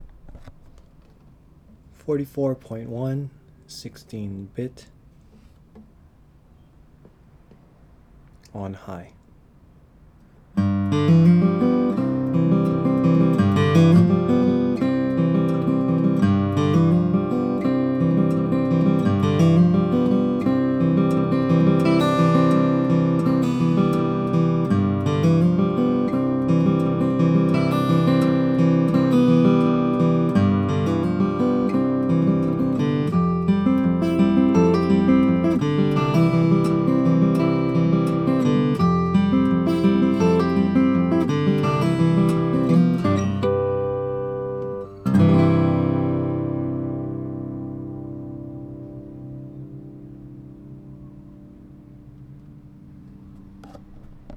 44.1 kHz, 16 bit, front and rear mics, mic pre set at High